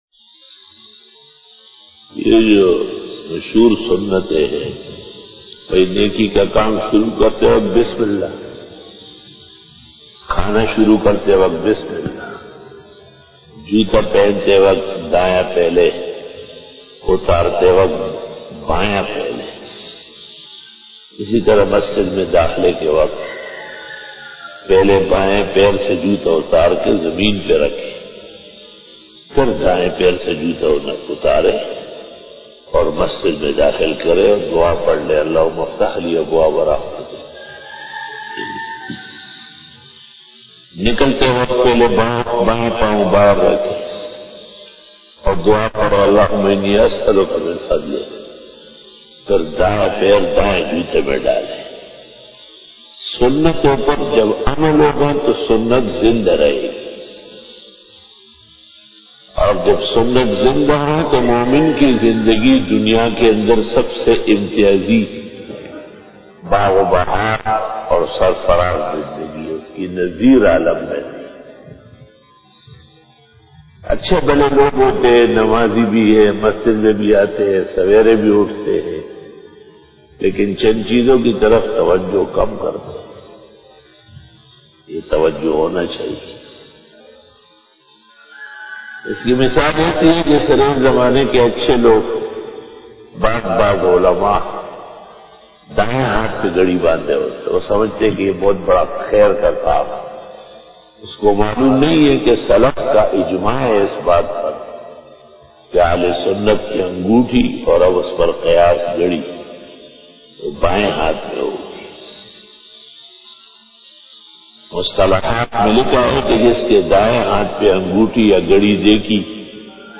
بیان بعد نماز فجر